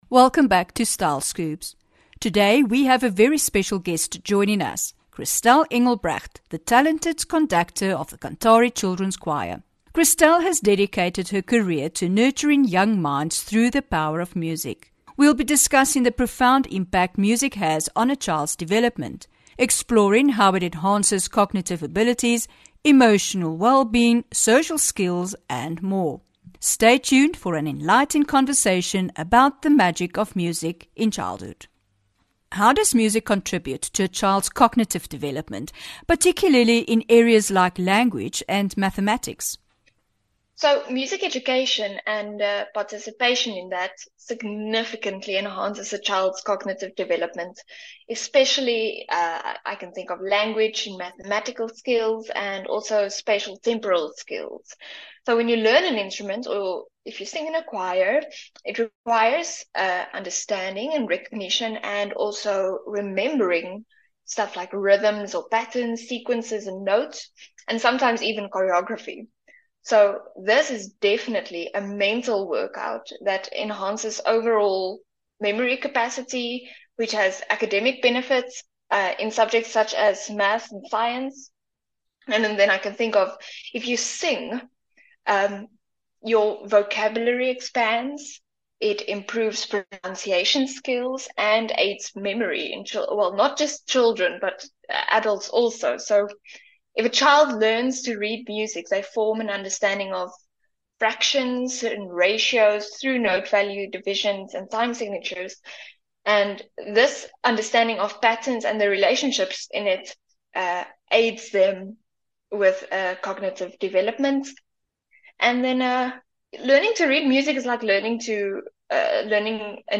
11 Jul INTERVIEW